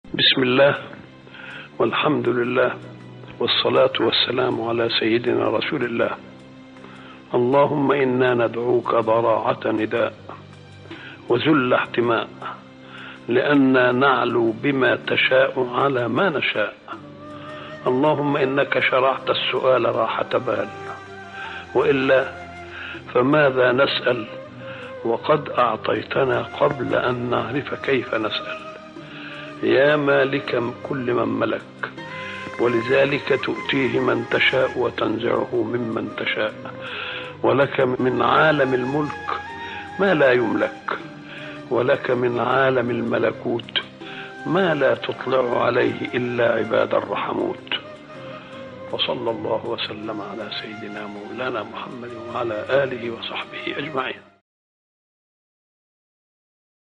دعاء خاشع ومناجاة مليئة بالضراعة والانكسار بين العبد وربه، يعترف فيه الداعي بعظمة الله وقدرته المطلقة على العطاء والمنع، ويختتم بالصلاة على النبي محمد صلى الله عليه وسلم.